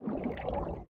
Minecraft Version Minecraft Version 1.21.5 Latest Release | Latest Snapshot 1.21.5 / assets / minecraft / sounds / entity / squid / ambient5.ogg Compare With Compare With Latest Release | Latest Snapshot